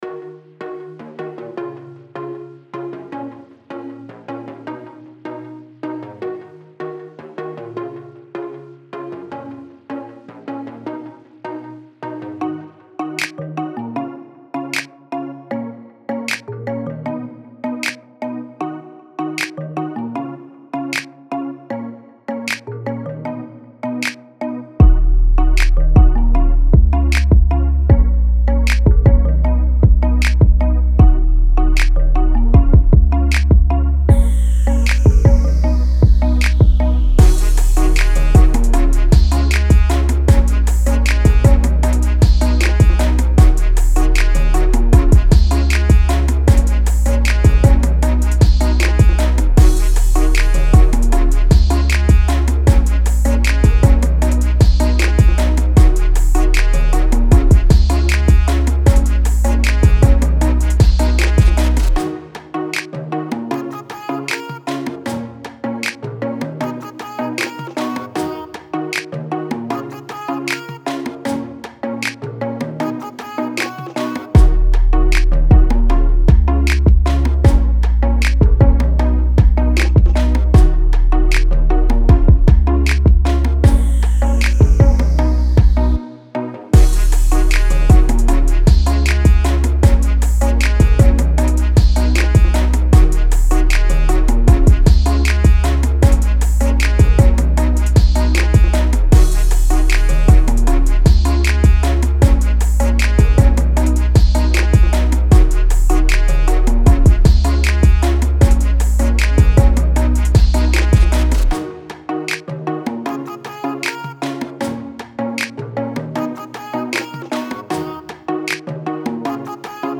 Pop
D# Min